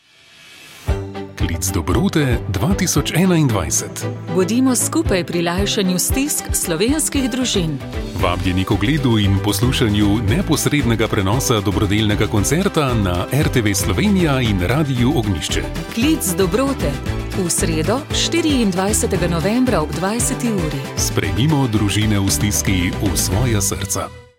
Radijski oglas 24s